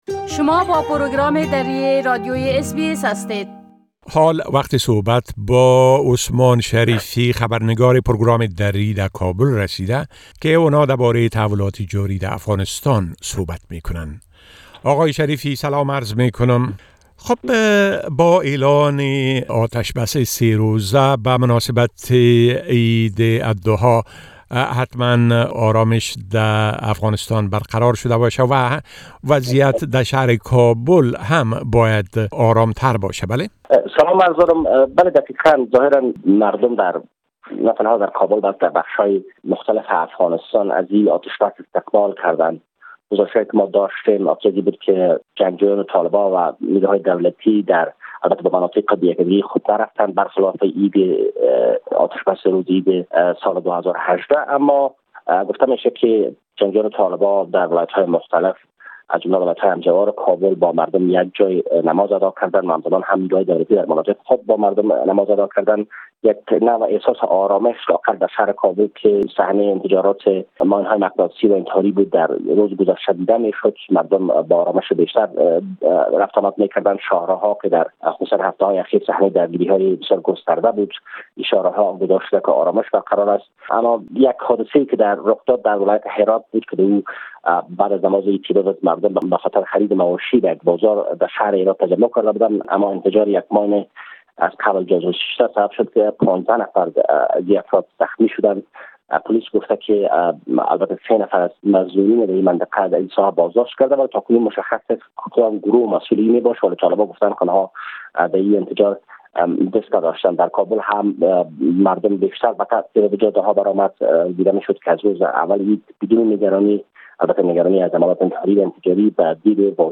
گزارش كامل خبرنگار ما در كابل بشمول اوضاع امنيتى و تحولات مهم ديگر در افغانستان را در اينجا شنيده ميتوانيد.